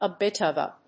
アクセントa bìt of a…